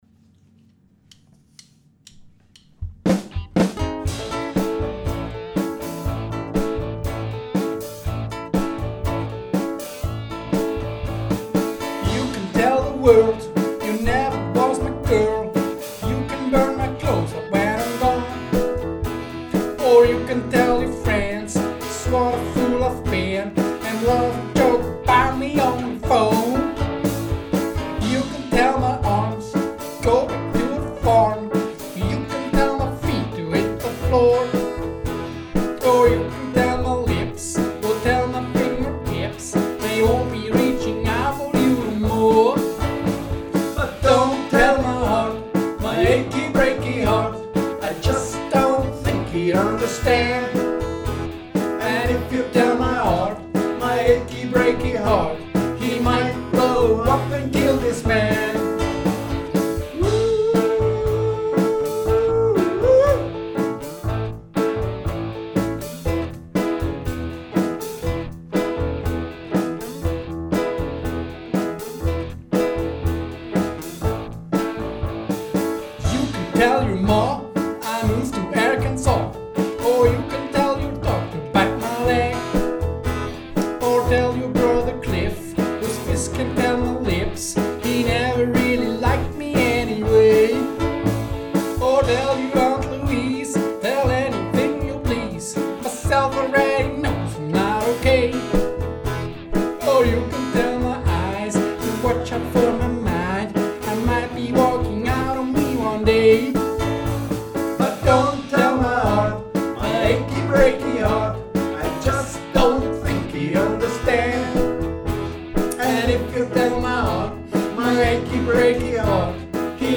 Cowboy-Song